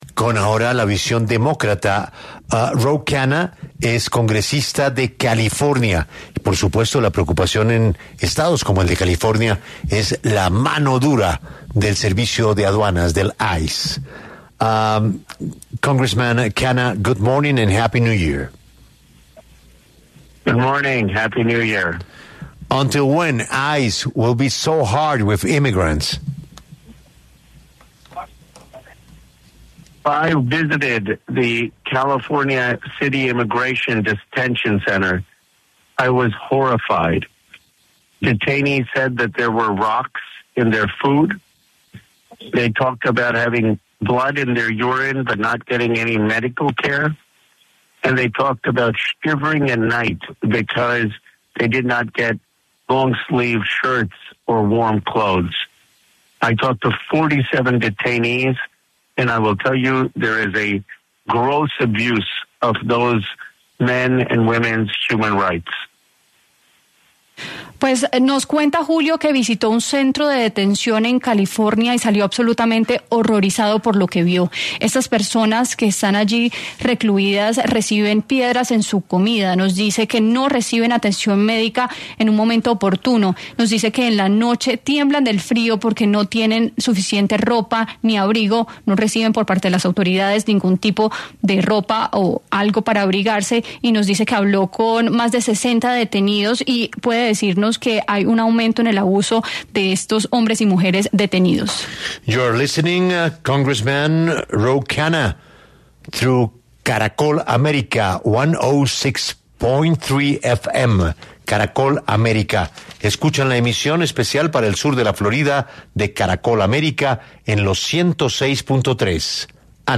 El congresista demócrata Ro Khanna, quien representa al 17o Distrito del Congreso de California, conversó con 6AM W acerca de las protestas en Estados Unidos en contra de las operaciones que hacen los agentes del Servicio de Control de Inmigración y Aduanas (ICE).